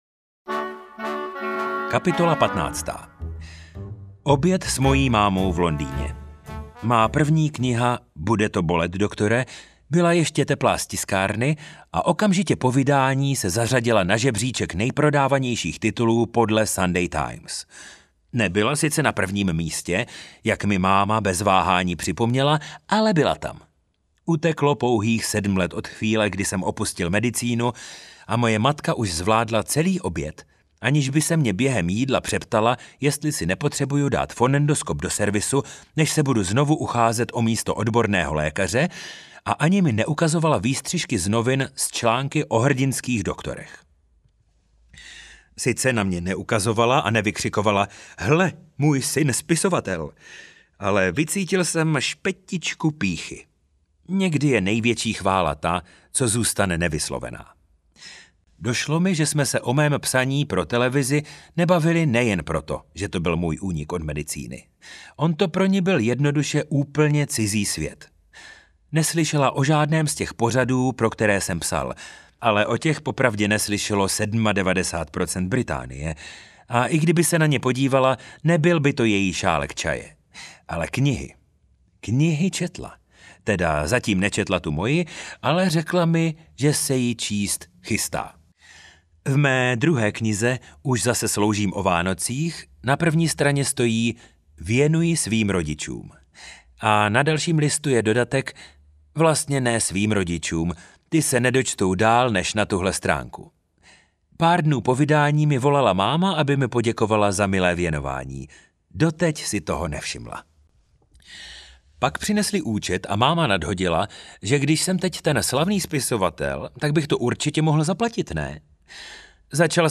Jak svět přichází o doktory audiokniha
Ukázka z knihy
jak-svet-prichazi-o-doktory-audiokniha